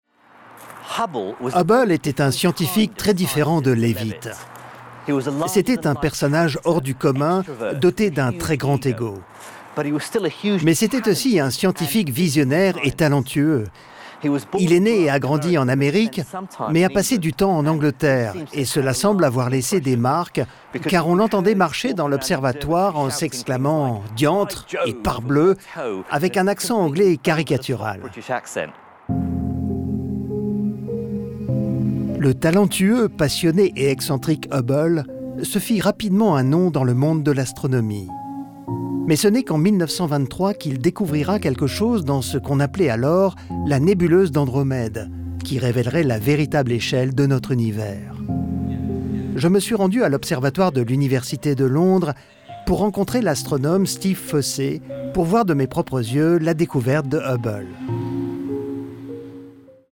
Voice-over in off